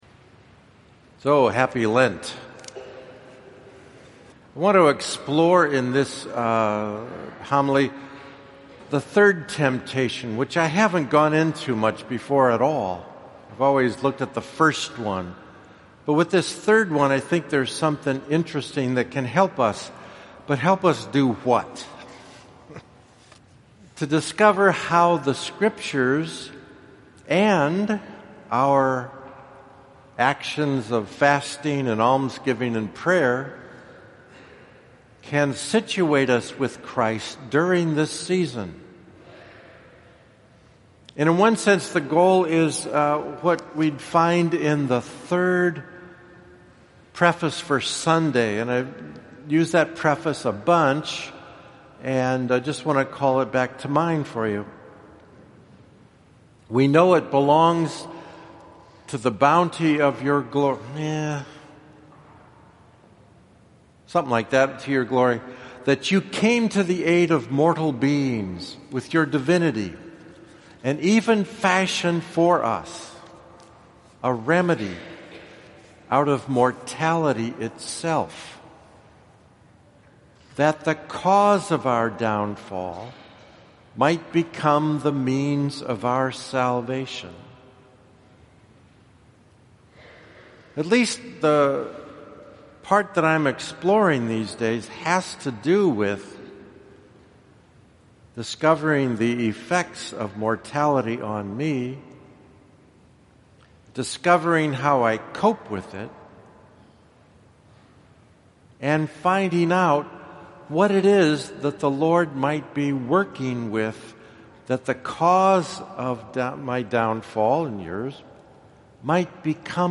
1st SUN LENT – Homily #2 / audio